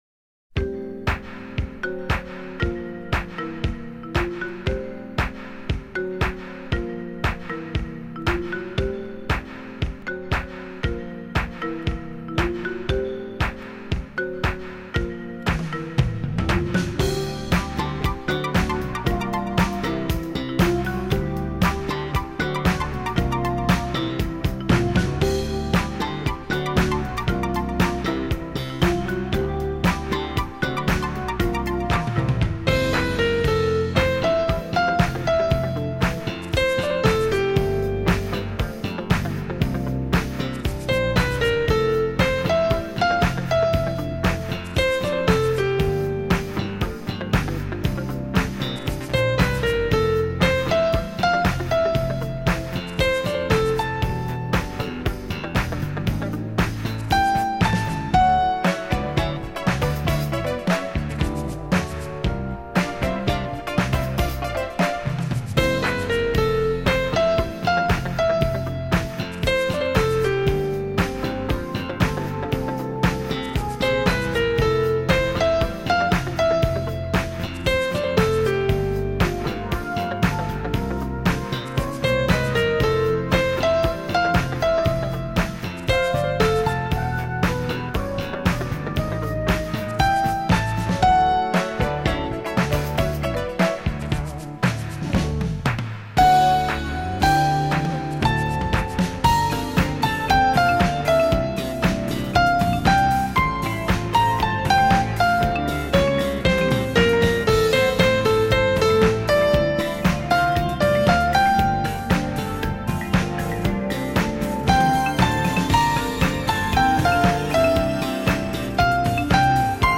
全明星乐队阵营精心制作，整张专辑一股清新明快之风会让您心情为之一振，愉快的心情久久沉醉于其中~